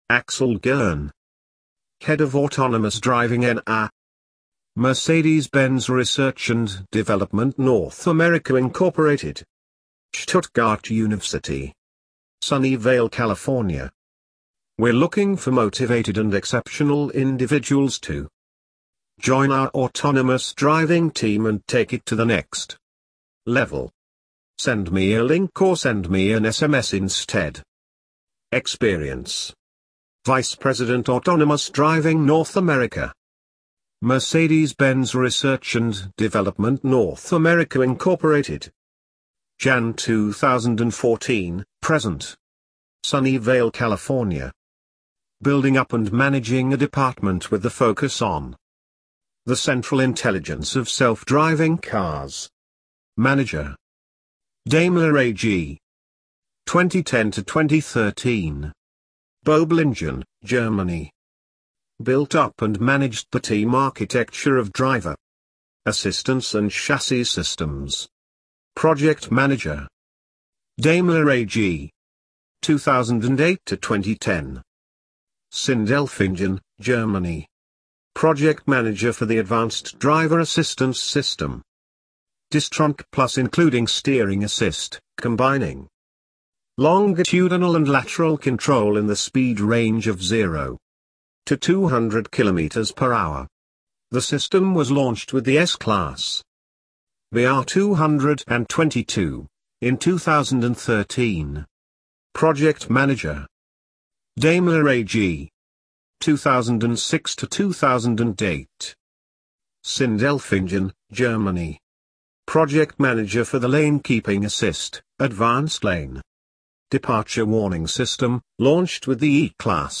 Linkedin - British TTS.mp3